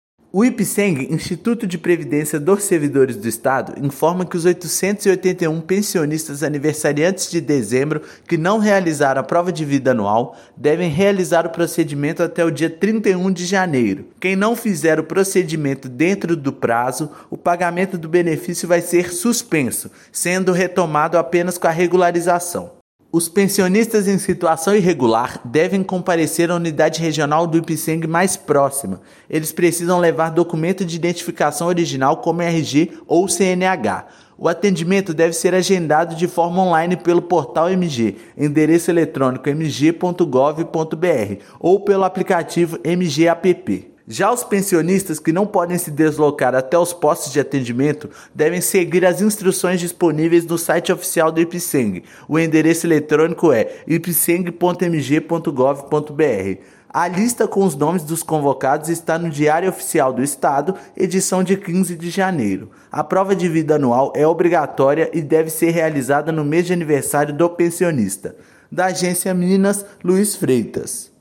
O procedimento deve ser realizado de forma presencial em uma das unidades regionais do Ipsemg. Ouça matéria de rádio.